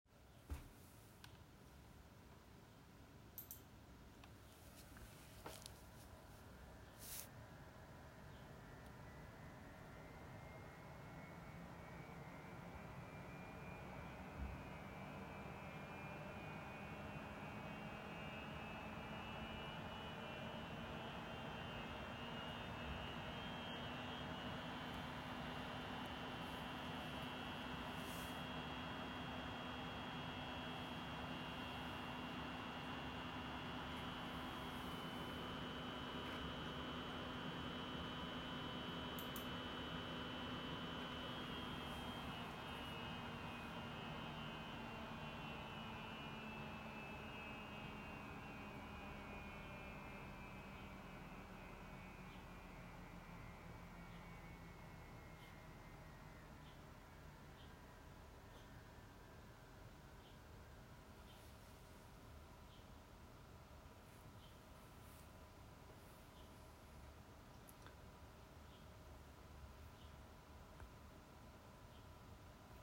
Die Gehäuselüfter rauschen nur, wie es sein soll. Wenn man im BIOS die Lüfter auf Full Speed stellt. Die CPU LÜfter rauschen nicht nur, die geben noch ein anderes Geräusch von sich.